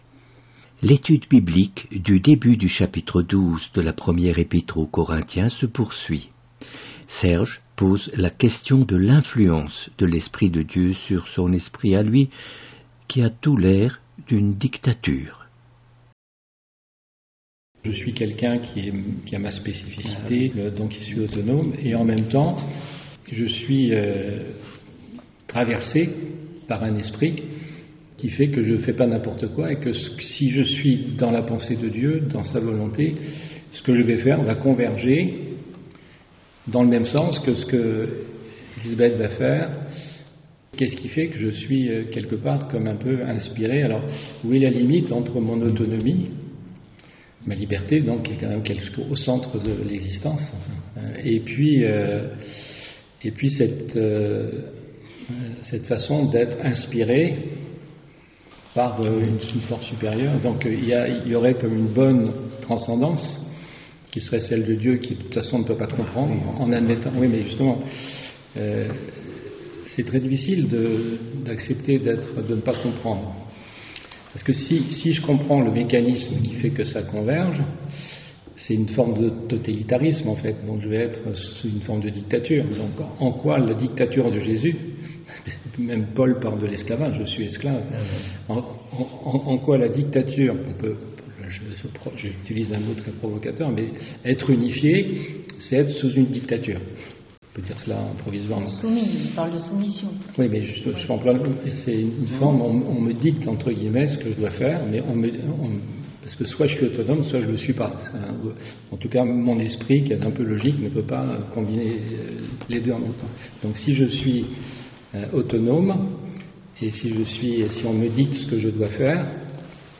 Etude biblique